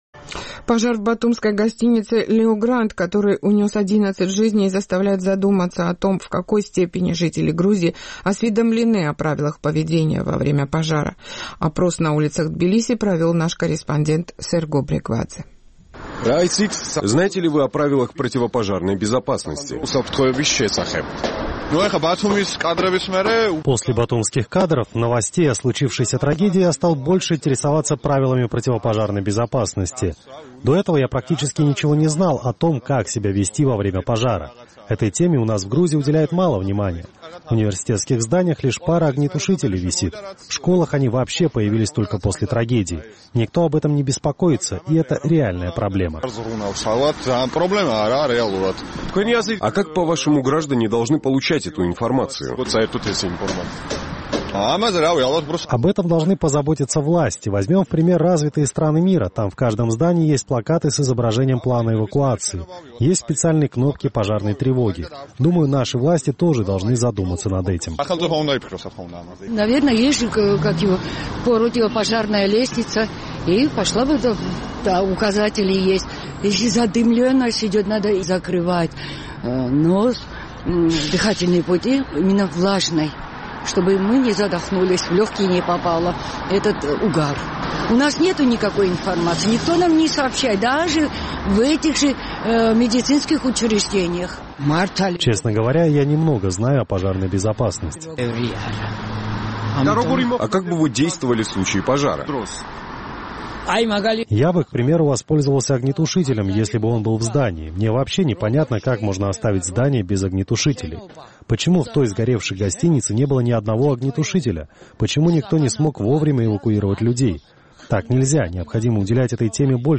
Пожар в батумской гостинице «Леогранд», который унес жизни 11 человек, заставляет задуматься о том, в какой степени жители Грузии осведомлены о правилах поведения во время пожара. Наш тбилисский корреспондент провел опрос на улицах грузинской столицы.